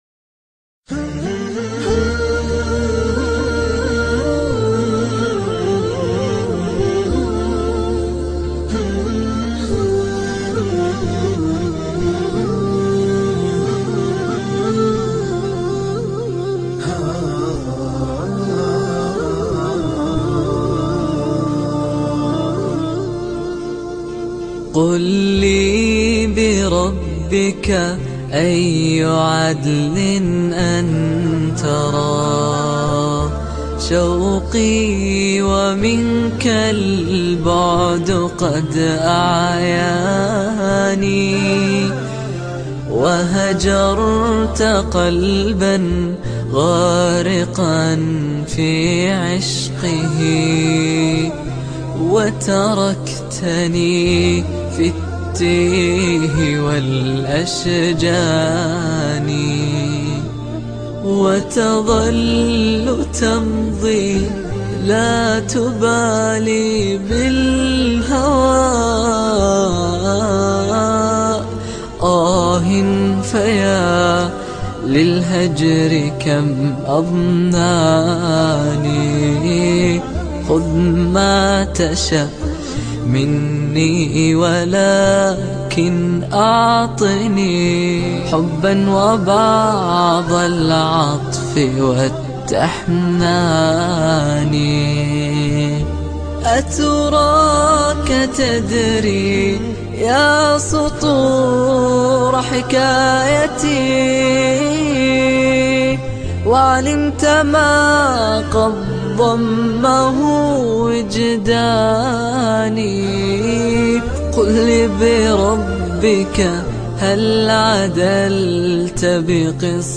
انشودة